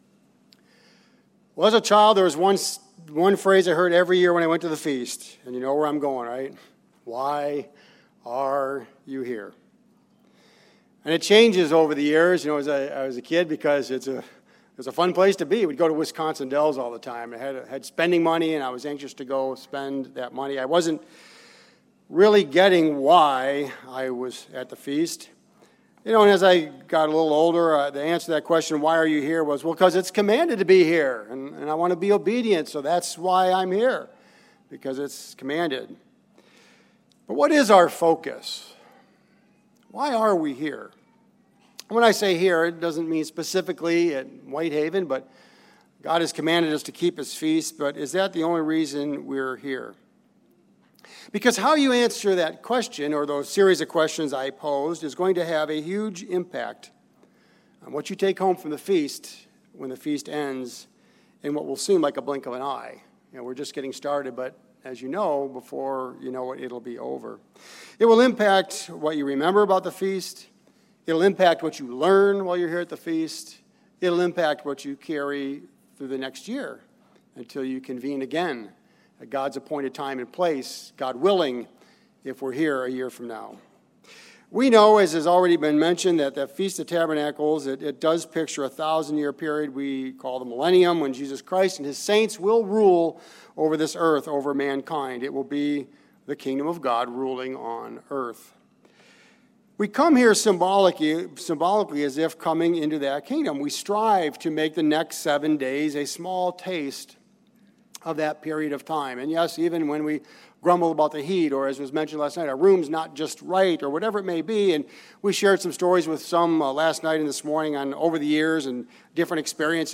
This sermon was given at the White Haven, Pennsylvania 2017 Feast site.